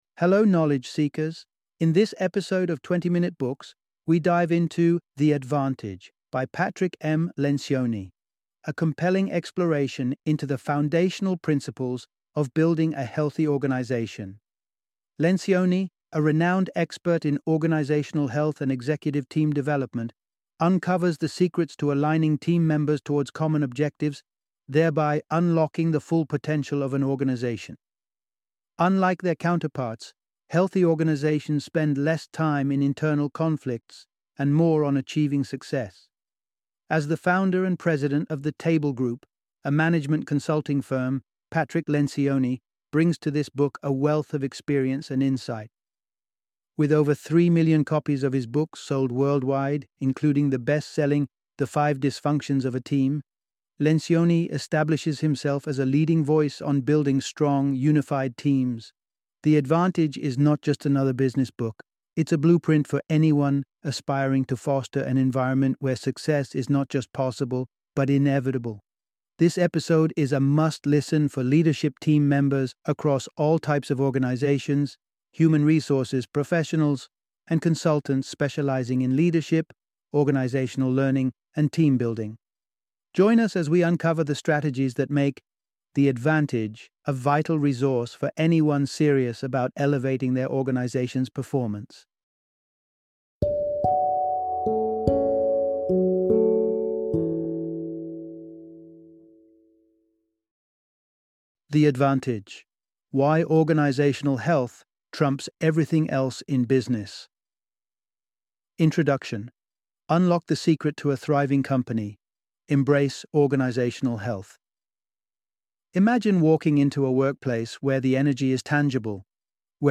The Advantage - Audiobook Summary